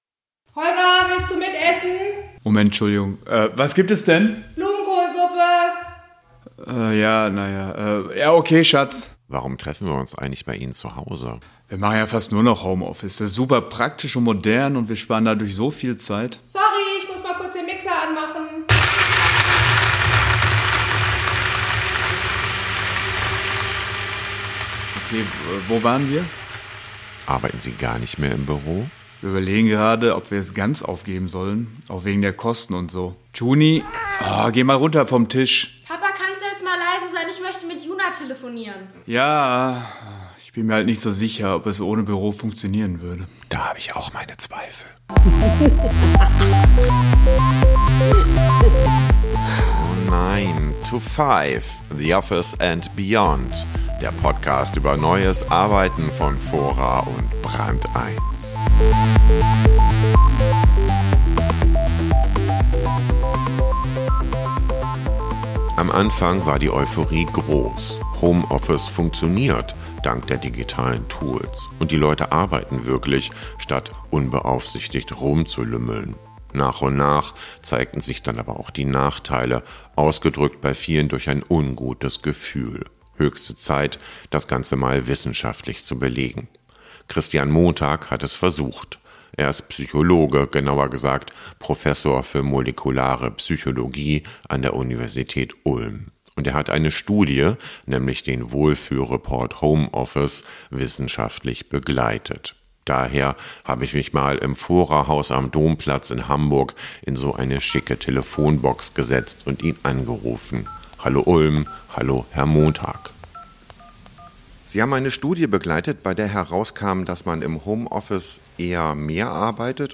Das Interview über fragmentierte Tage und Arbeiten auf der Toilette wurde per Telefon aus dem Fora Haus am Domplatz in Hamburg geführt.